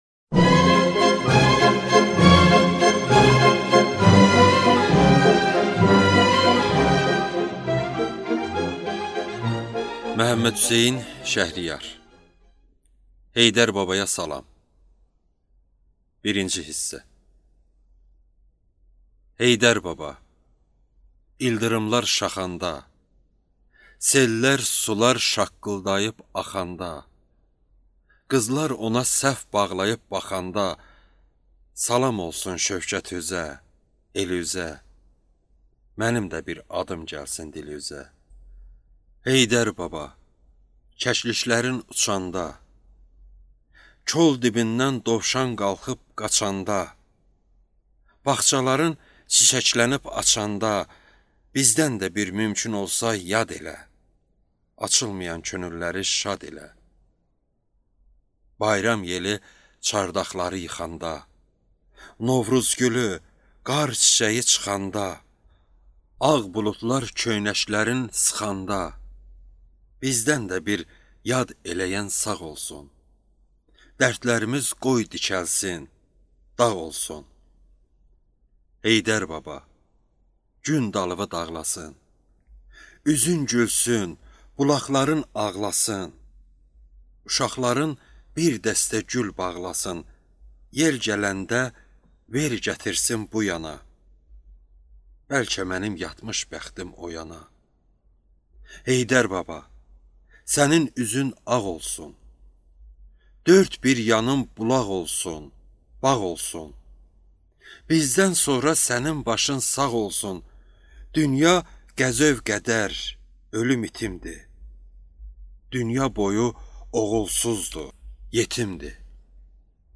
Аудиокнига Heydərbabaya salam | Библиотека аудиокниг